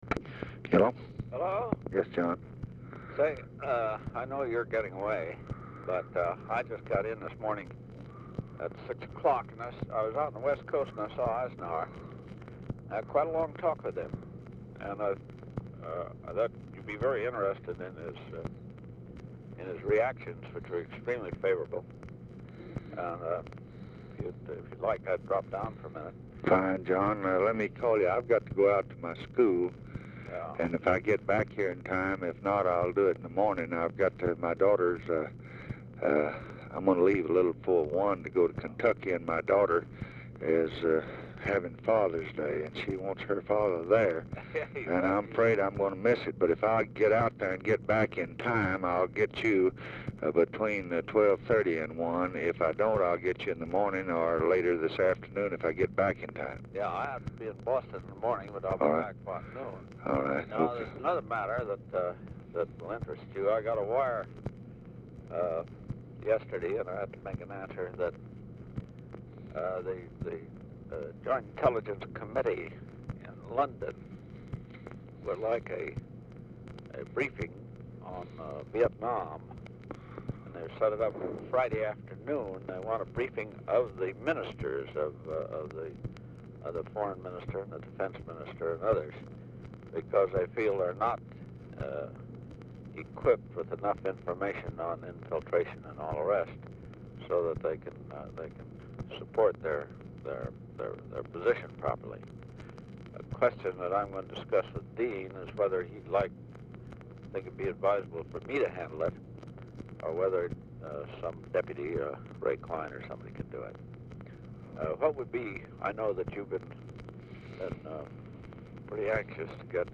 Telephone conversation # 6869, sound recording, LBJ and JOHN MCCONE, 2/22/1965, 10:45AM | Discover LBJ
Format Dictation belt
Location Of Speaker 1 Mansion, White House, Washington, DC